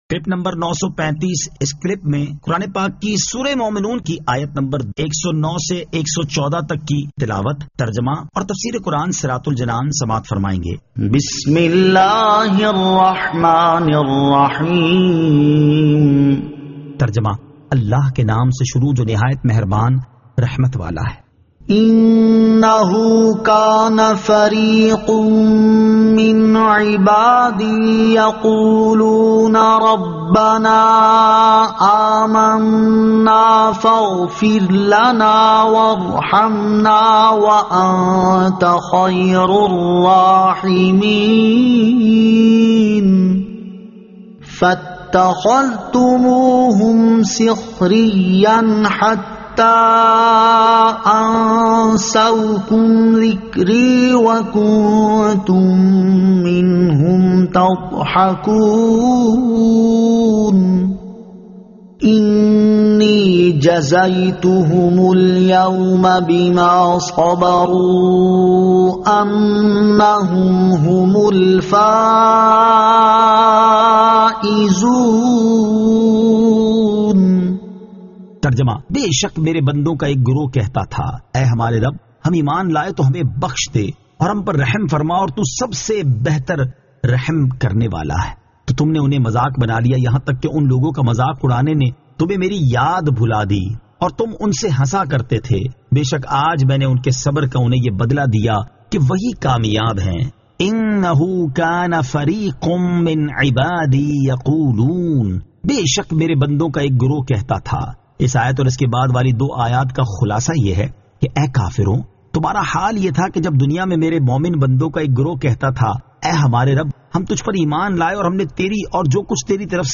Surah Al-Mu'minun 109 To 114 Tilawat , Tarjama , Tafseer